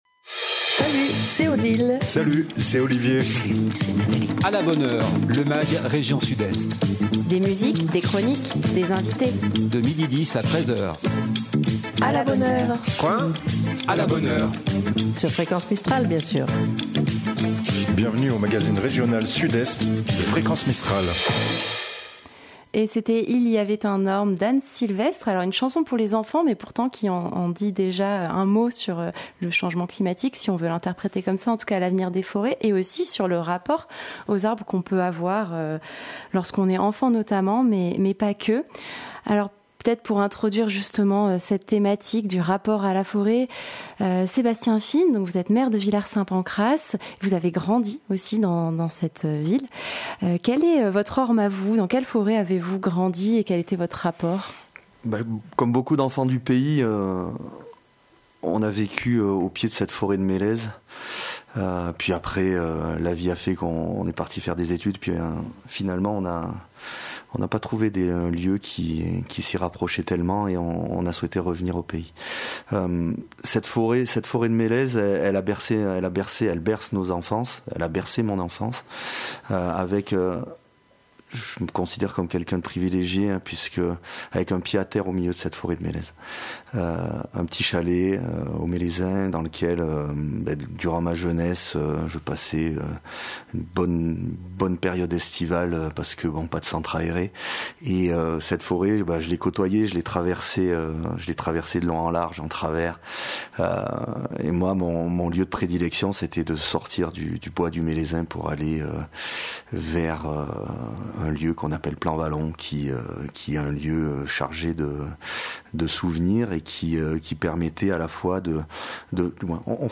Sébastien Fine, maire de Villard Saint Pancrace